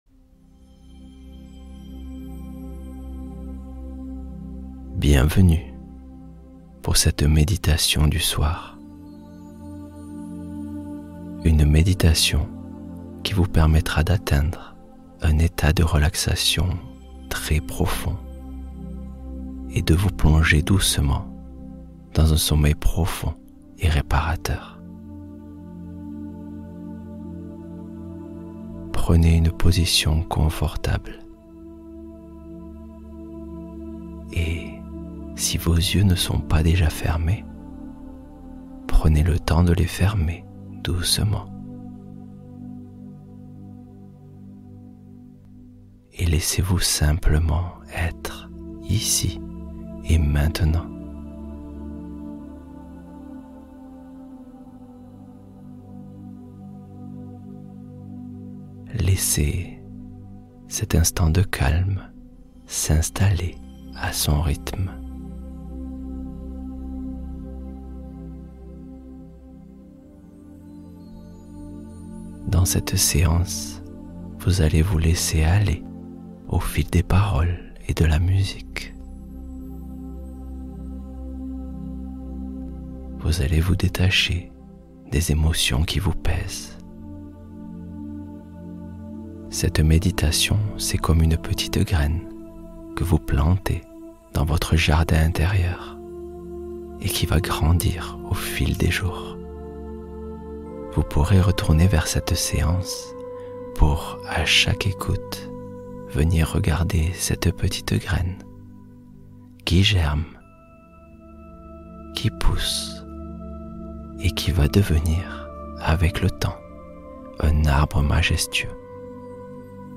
Sommeil profond : relaxation qui plonge dans la nuit